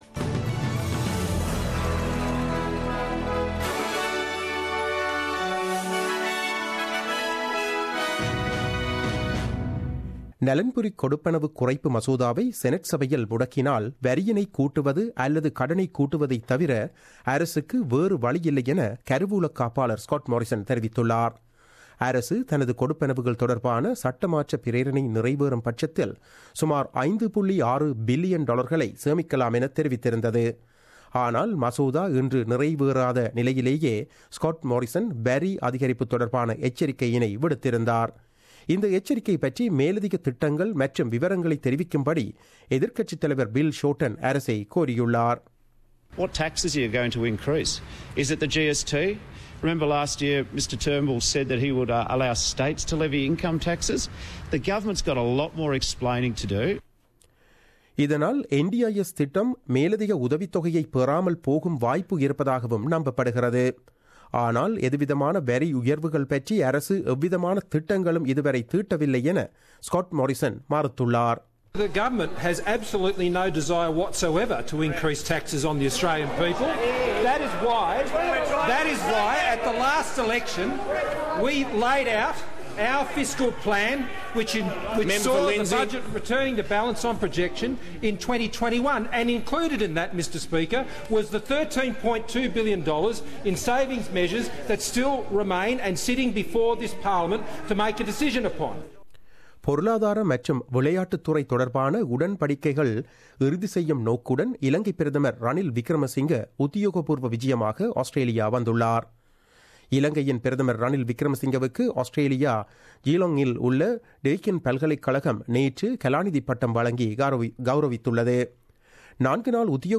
The news bulletin aired on 15 February 2017 at 8pm.